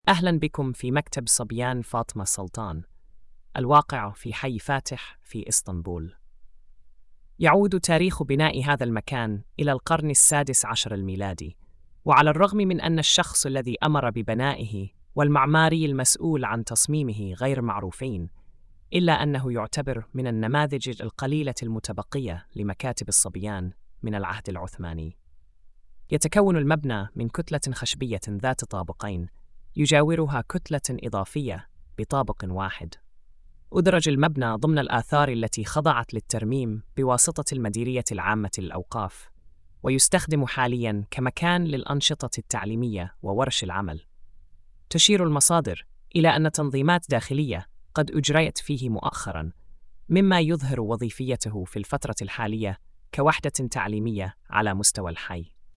السرد الصوتي: